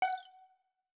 Game Warning Notification Sound.wav